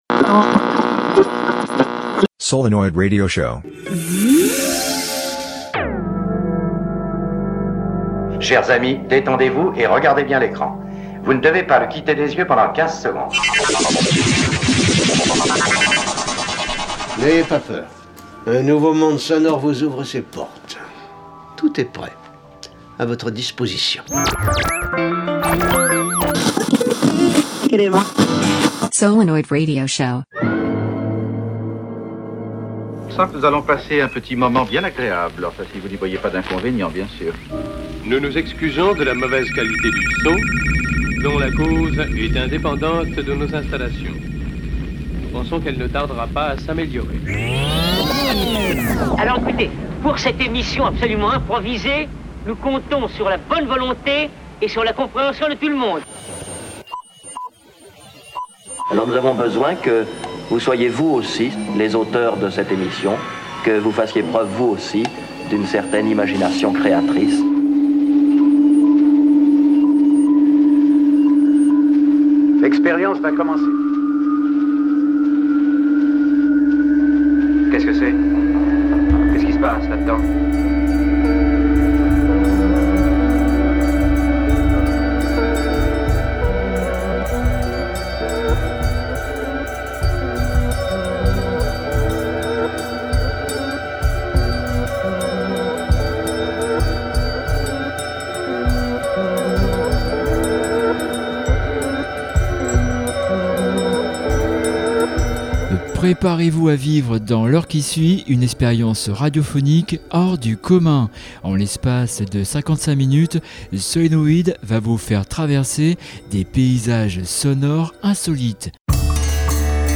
trio distillant un élixir sonore au pouvoir mystérieux.